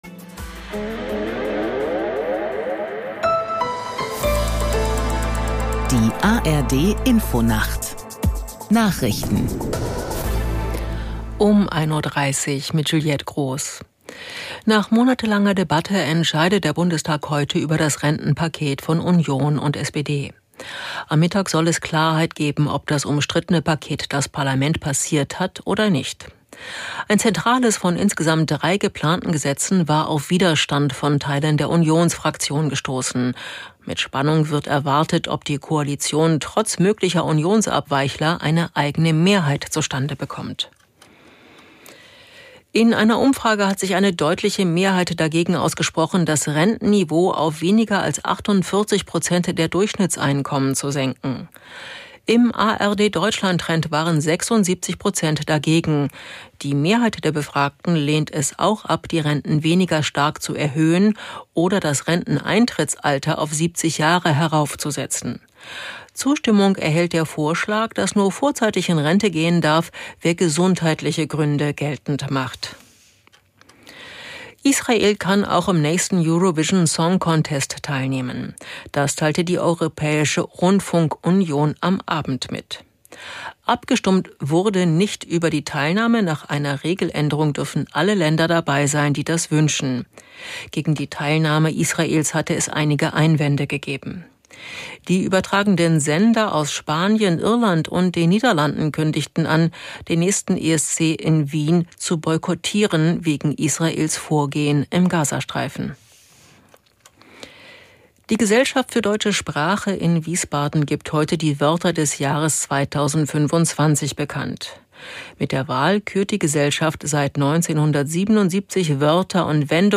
Die aktuellen Meldungen aus der NDR Info Nachrichtenredaktion.